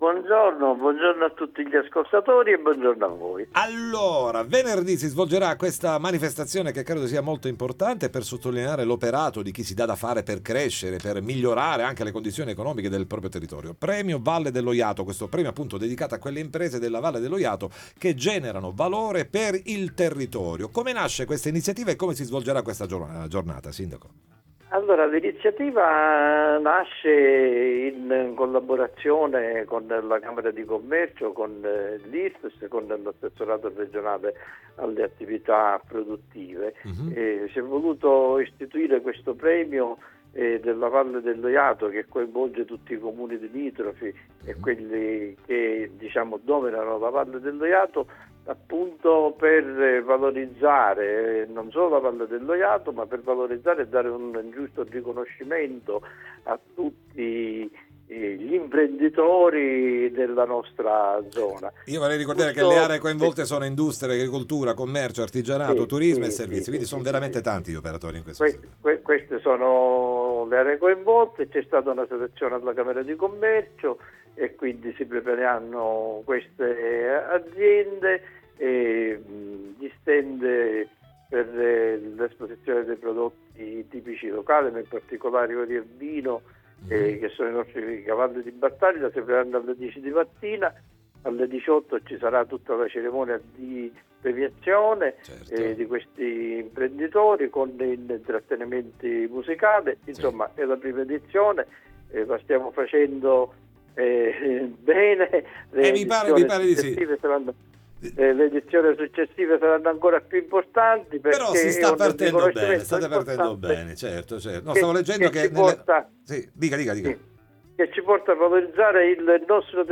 Premio Valle dello Jato, ne parliamo con Giuseppe Siviglia, Sindaco di San Giuseppe Jato
Premio Valle dello Jato intervista Time Magazine 26/11/2025 12:00:00 AM / Time Magazine Condividi: Premio Valle dello Jato, ne parliamo con Giuseppe Siviglia, Sindaco di San Giuseppe Jato